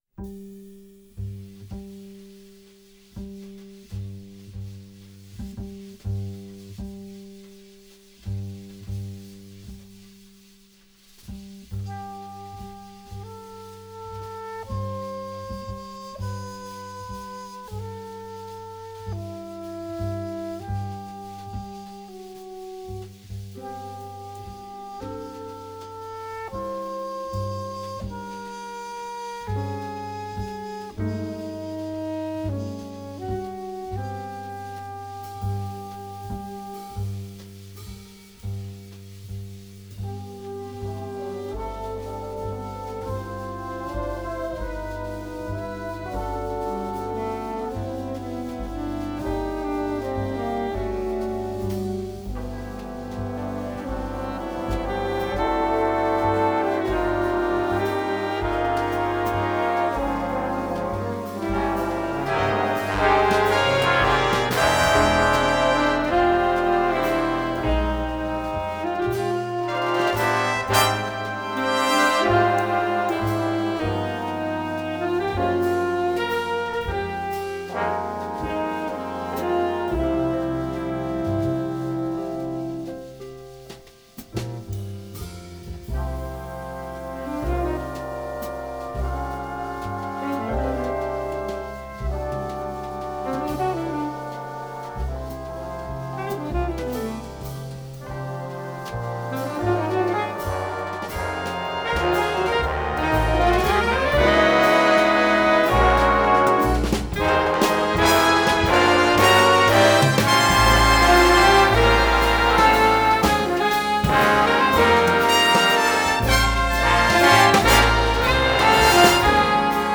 Piano
Guitar
Bass
Drums